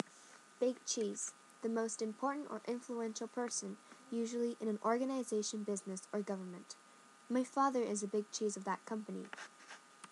英語ネイティブによる発音は下記のリンクをクリ ックしてください。